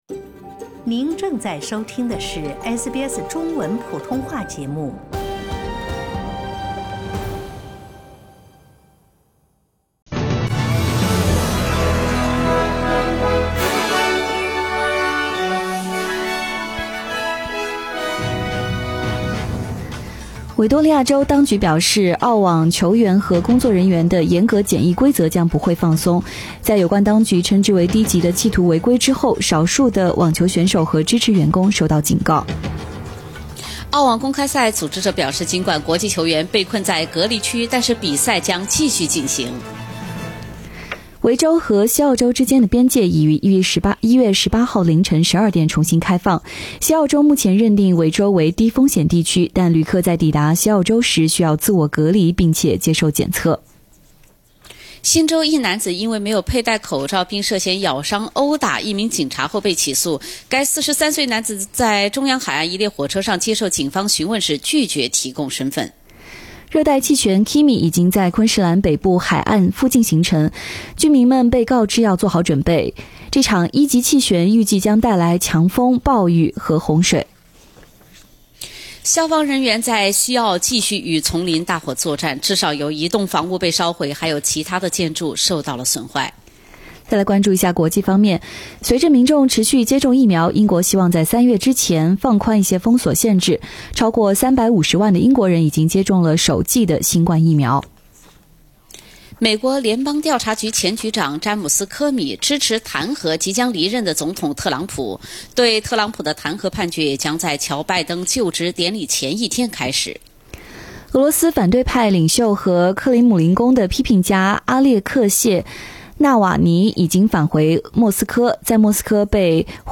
SBS早新聞（1月18日）